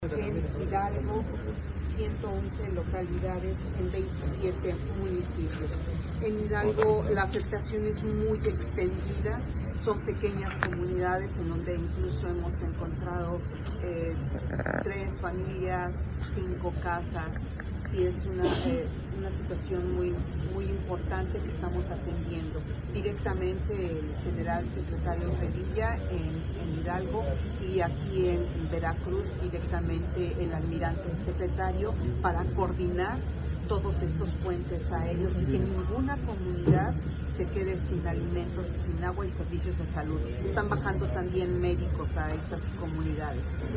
Entrevistada en el Aeropuerto Tajín de Poza Rica, el cual está funcionando.